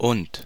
Ääntäminen
standard: IPA: /ʊnt/